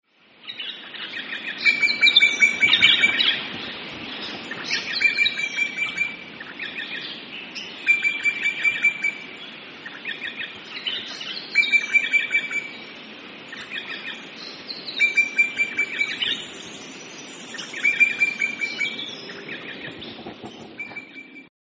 Voice: noisy chatter, bell-like call, raucous screech.
Call 3: pink-pink-pink call
Adel_Rosella_pink.mp3